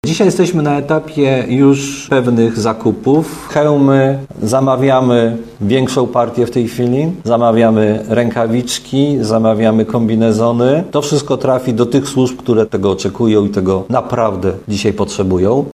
Mówi prezydent Tarnobrzega, Dariusz Bożek.